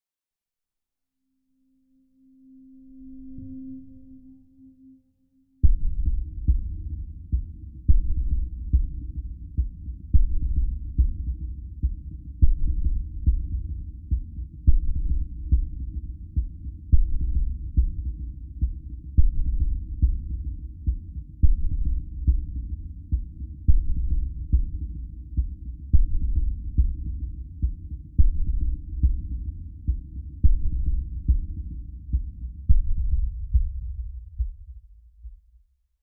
STest1_Mono200Hz.flac